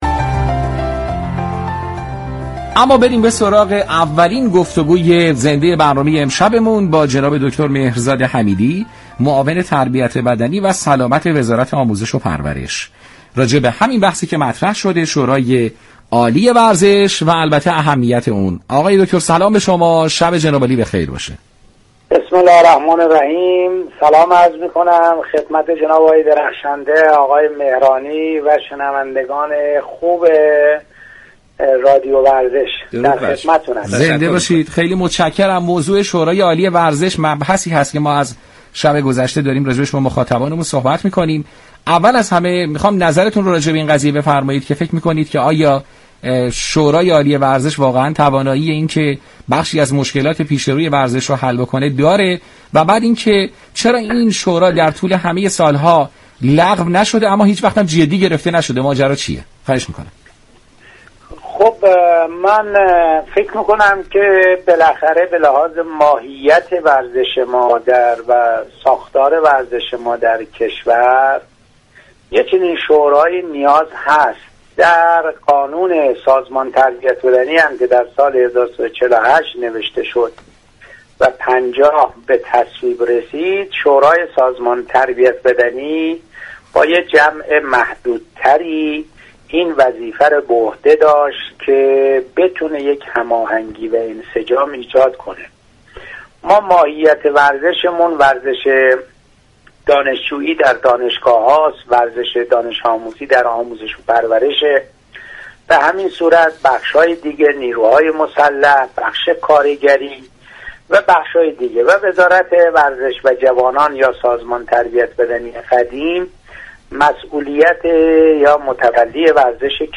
شما مخاطب محترم می توانید از طریق فایل صوتی پیوست شنونده ادامه این گفتگو باشید.